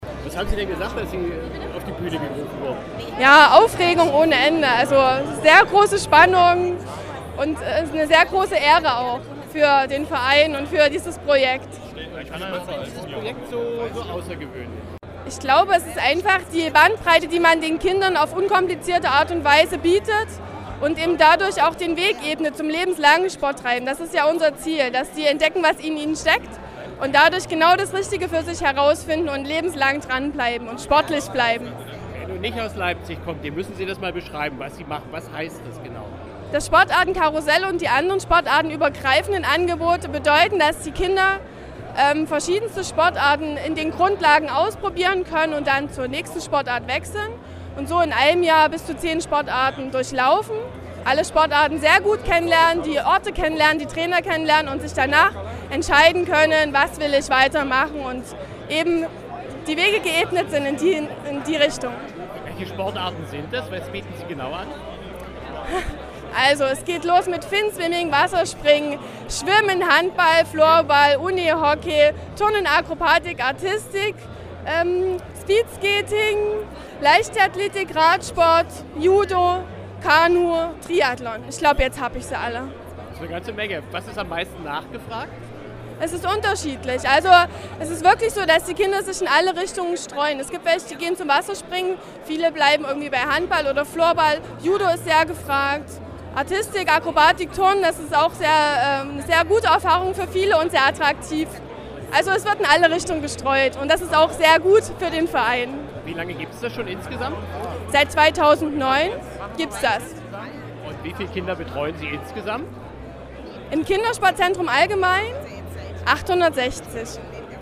Magazin: Sterne des Sports
Beitrag.